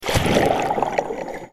Gaia Ambient Submerge Down.mp3